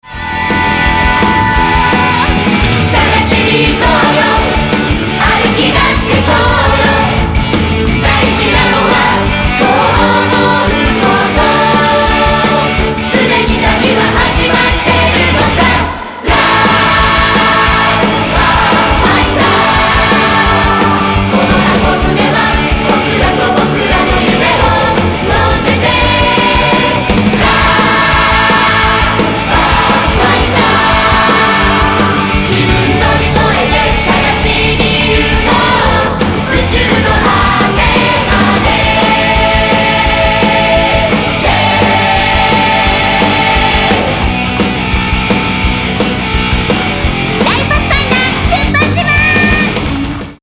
※課題曲は2声に分かれています。
基本男性が上パート、女性が下パートですが、希望があればその場で申し出てください。